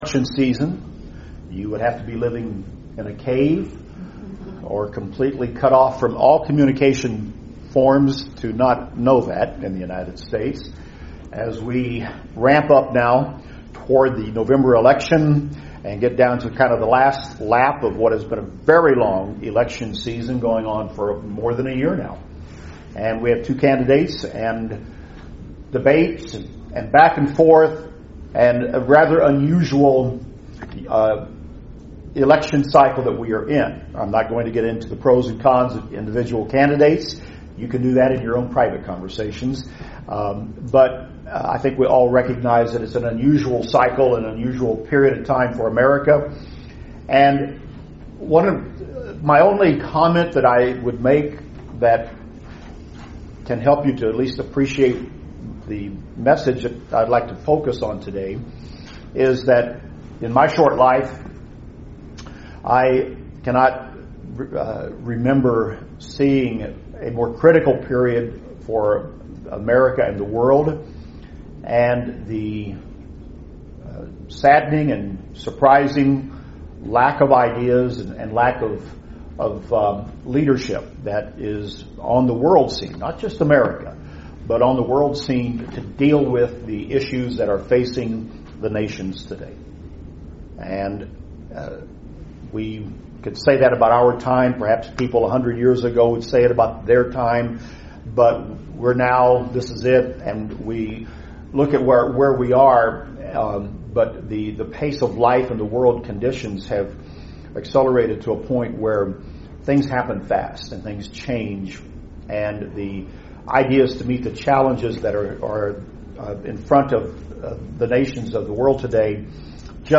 Are you being prepared for the Kingdom of God? This and other questions are answered in this sermon.